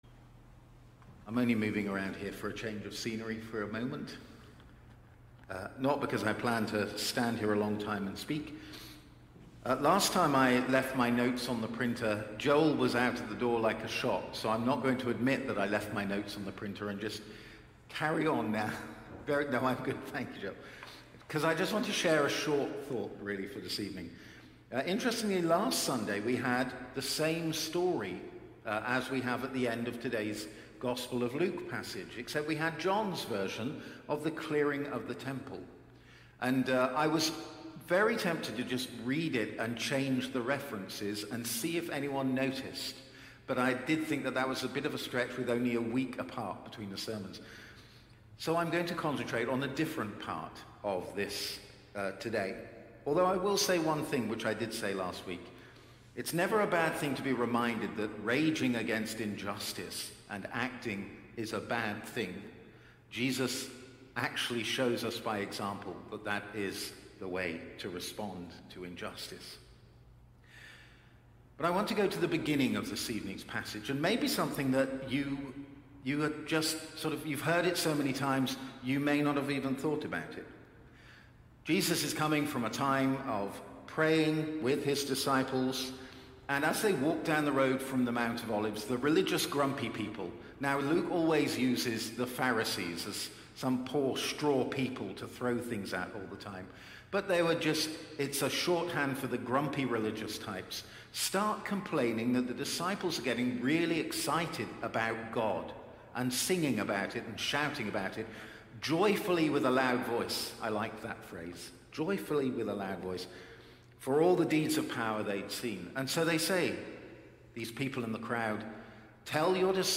Sermons | St. John the Divine Anglican Church
Evening Worship Reflection